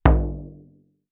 drum_hit0001.wav